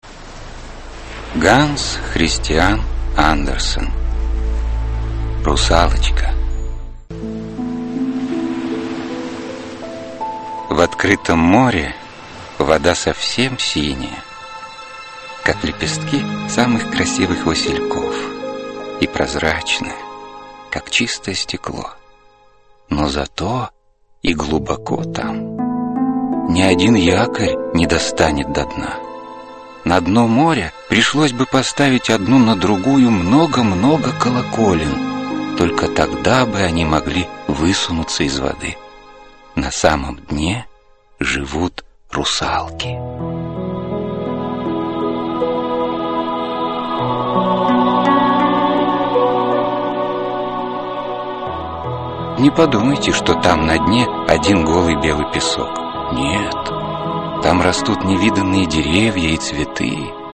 Аудиокнига Русалочка (спектакль) | Библиотека аудиокниг
Aудиокнига Русалочка (спектакль) Автор Ганс Христиан Андерсен Читает аудиокнигу Алина Покровская.